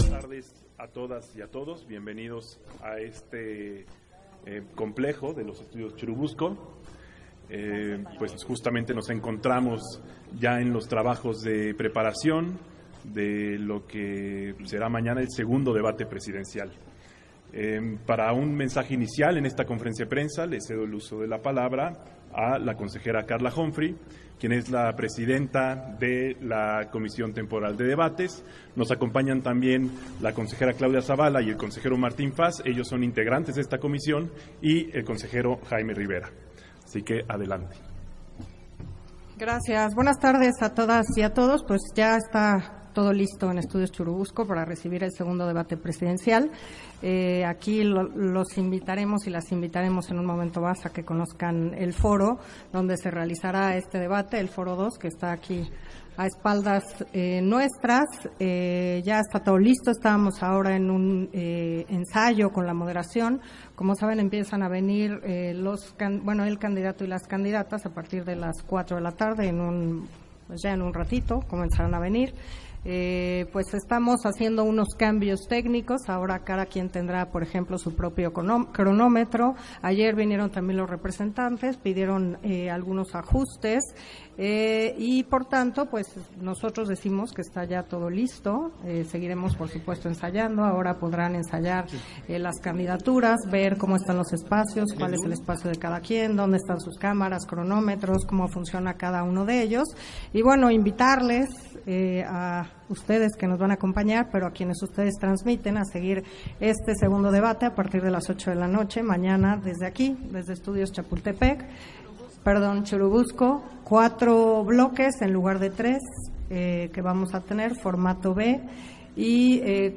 270424_AUDIO_CONFERENCIA-DE-PRENSA - Central Electoral